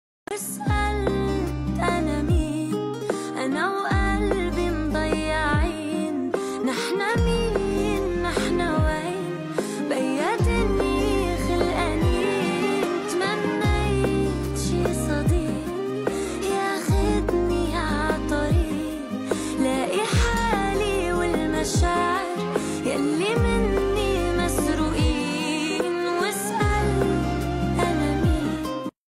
اللحن والتوزيع: مزيج من الحزن والغموض
غنّت الأغنية بأسلوب يمزج بين الحيرة والألم